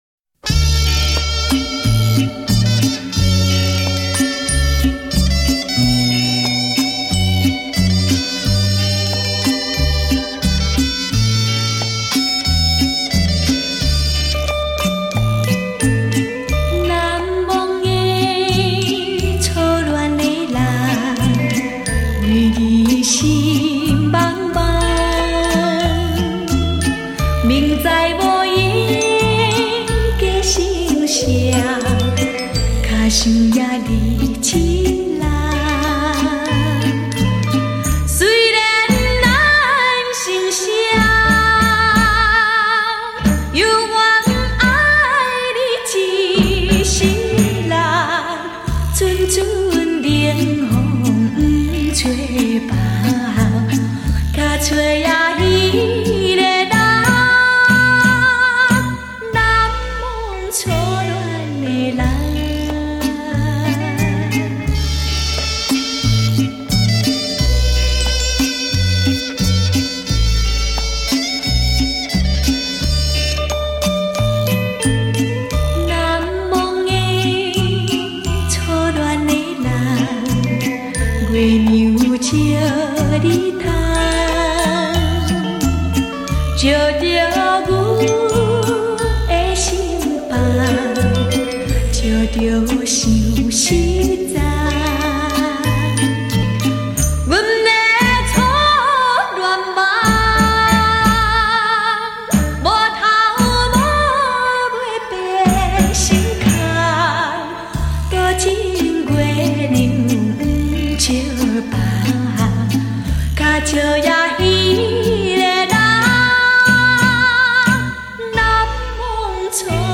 聆觅丽影君情歌华倩韵 黑胶唱片原音回放
首度以高保真CD正式出版 原始母带经高新科技原音处理
既保留了黑胶唱片的暖和柔美 也展现了数码唱片的精确清晰
让那甜蜜柔美的声音再度飘进你的心窝 萦牵你的心弦